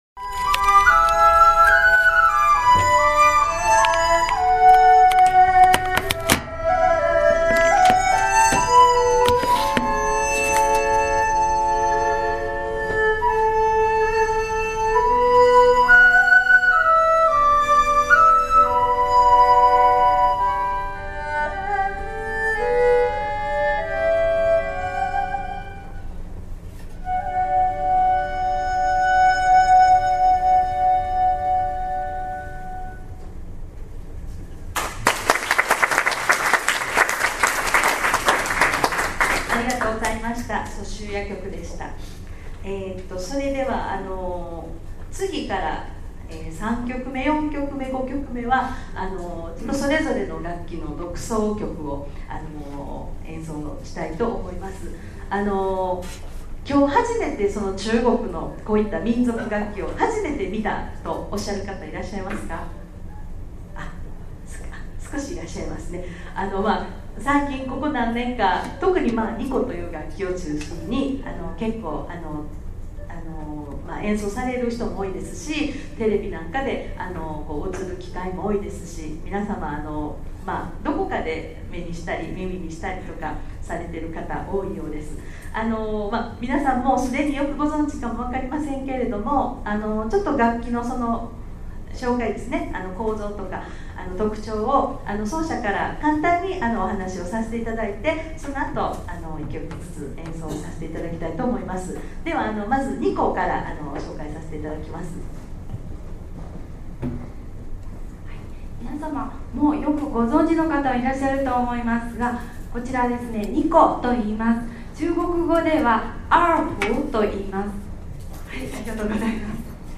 二胡 ・笙・笛 ：平成23年7月3日(日)